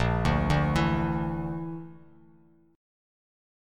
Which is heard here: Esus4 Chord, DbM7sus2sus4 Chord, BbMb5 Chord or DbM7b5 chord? BbMb5 Chord